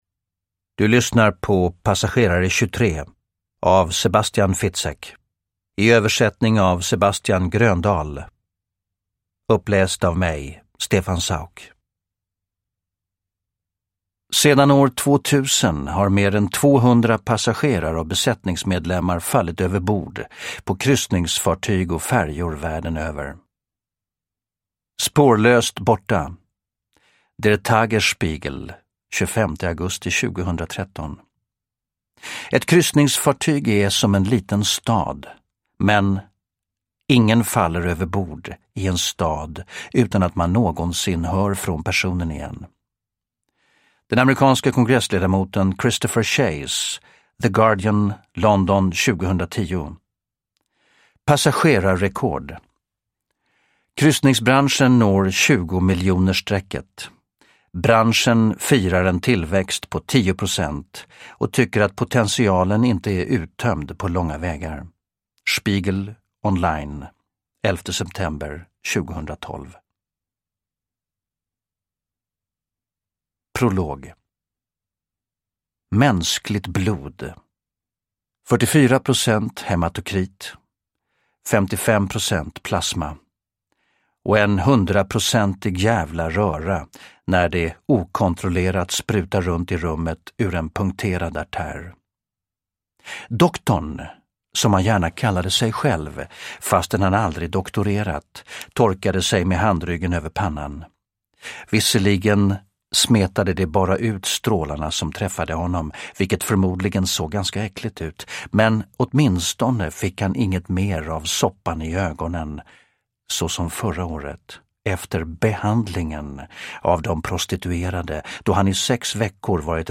Passagerare 23 – Ljudbok – Laddas ner
Uppläsare: Stefan Sauk